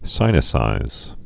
(sīnĭ-sīz, sĭnĭ-)